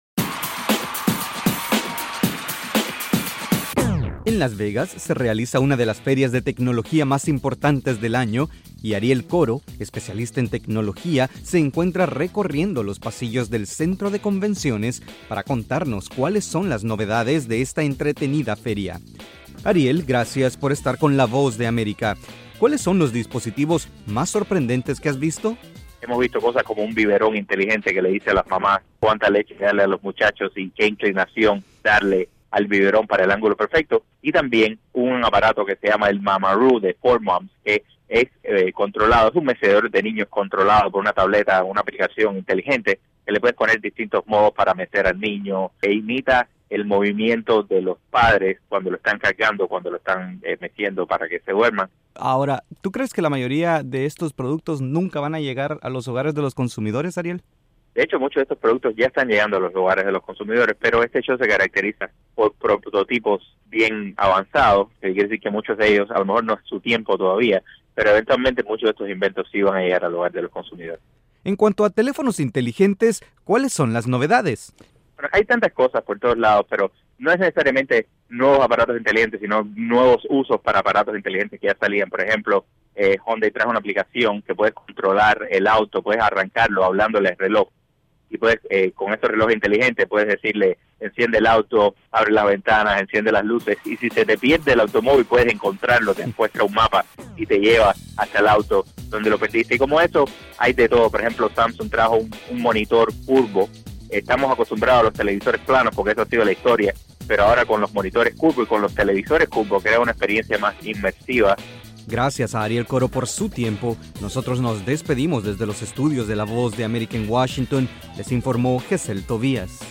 El Consumer Electronics Show, conocido por sus siglas en inglés CES, y que se desarrolla en Las Vegas, es uno de los eventos tecnológicos más importantes de Estados Unidos. Desde los estudios de la Voz de América en Washington informa